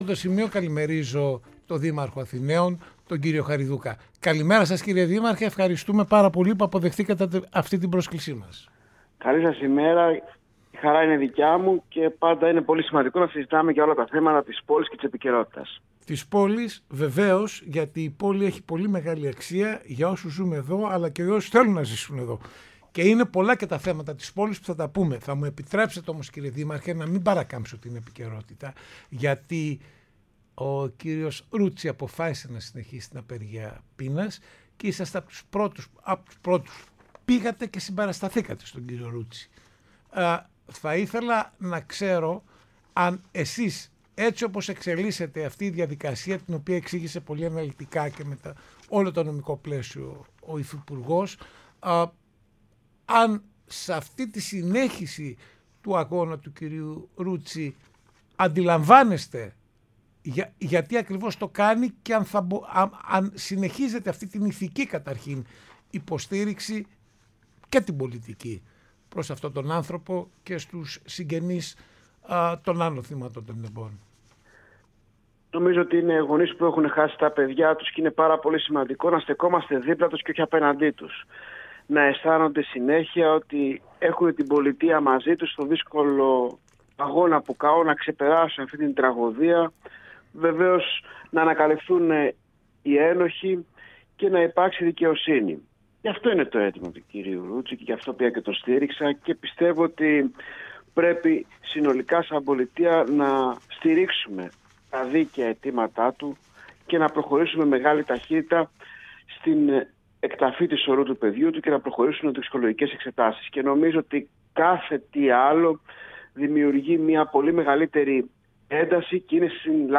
Ο Δήμαρχος Αθηναίων έδωσε σήμερα συνέντευξη στο ERTNews Radio